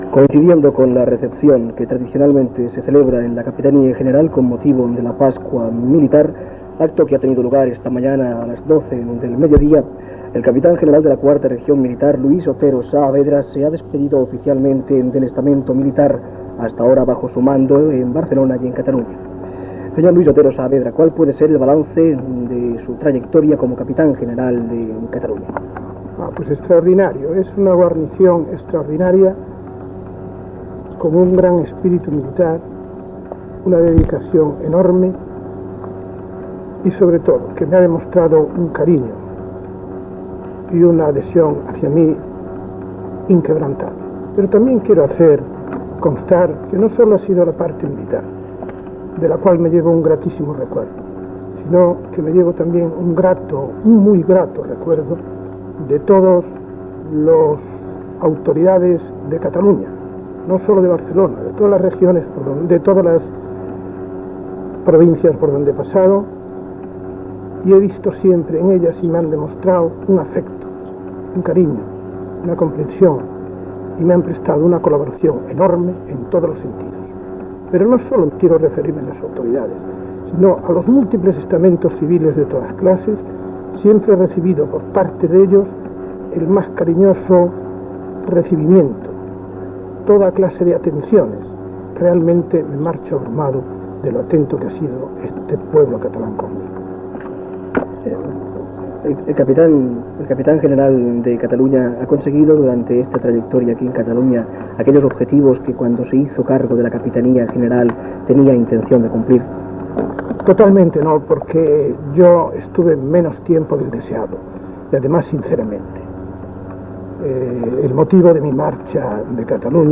Entrevista a Luis Otero Saavedra, que deixava la capitania general de Catalunya
Informatiu